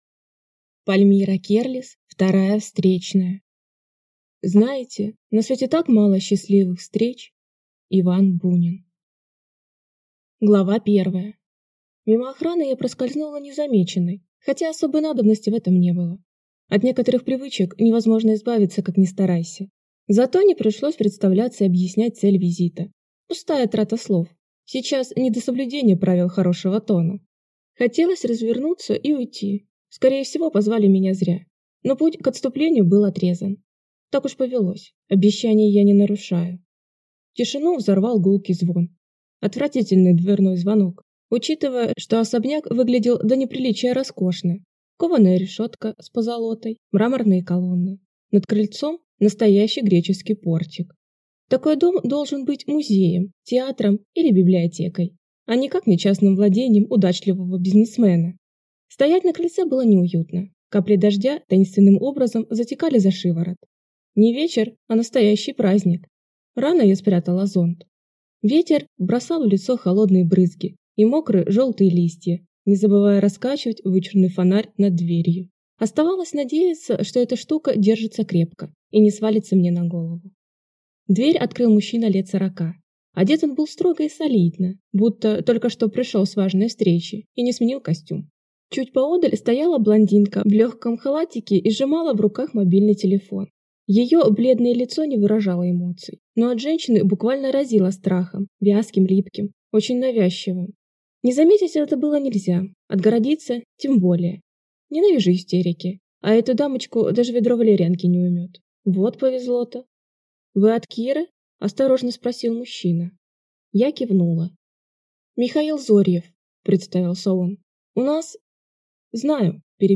Аудиокнига Вторая встречная - купить, скачать и слушать онлайн | КнигоПоиск